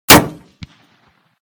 / gamedata / sounds / material / bullet / collide / metall04gr.ogg 16 KiB (Stored with Git LFS) Raw History
metall04gr.ogg